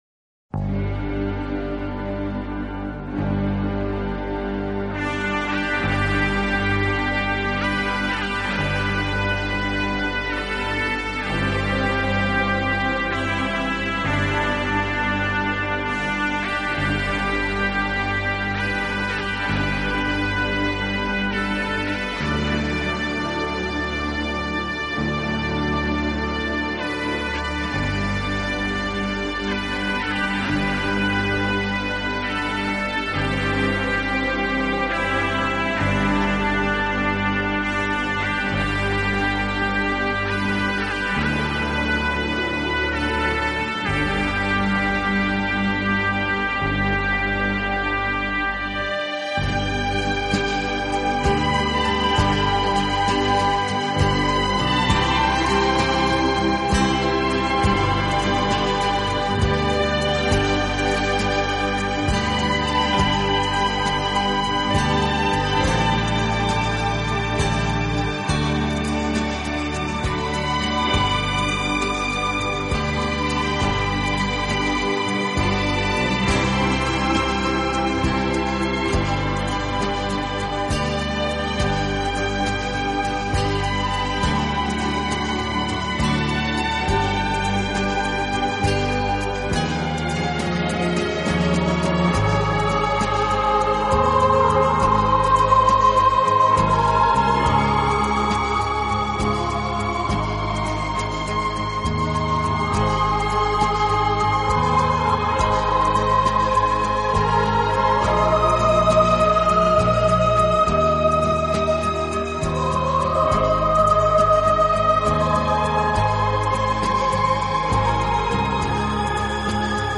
轻音乐